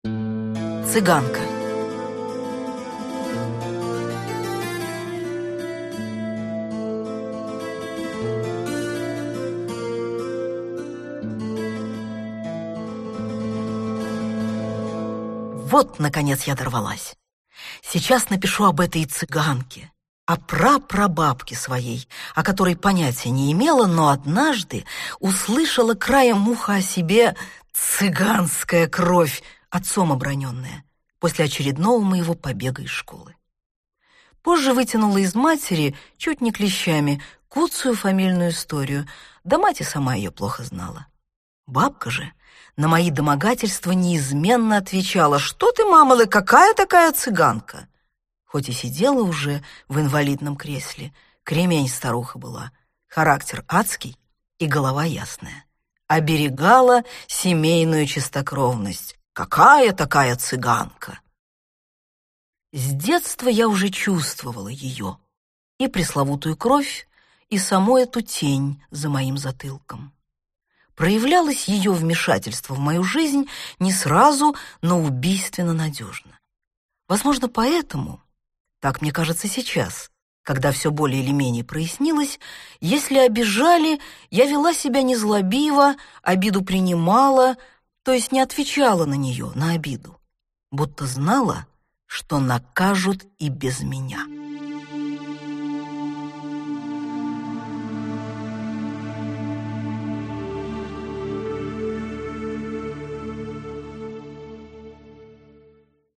Аудиокнига Цыганка (сборник) | Библиотека аудиокниг
Aудиокнига Цыганка (сборник) Автор Дина Рубина Читает аудиокнигу Дина Рубина.